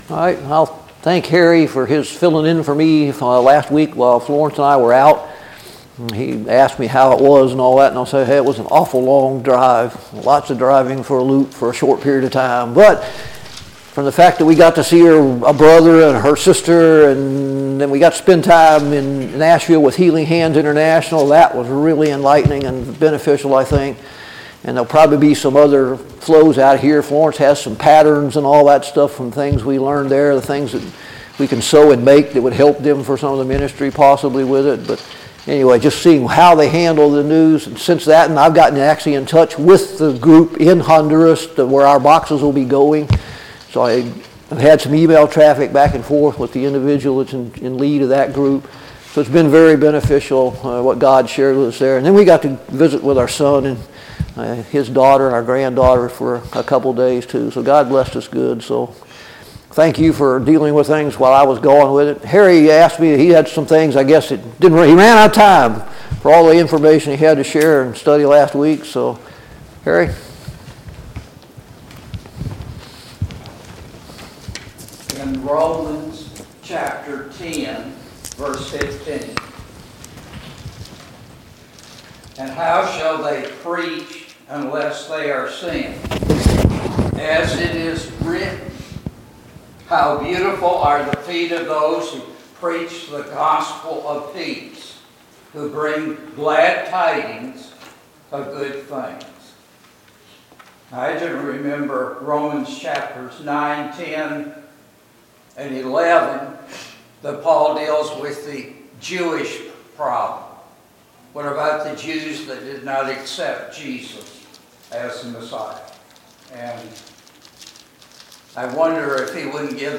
Zephaniah Service Type: Sunday Morning Bible Class « 16.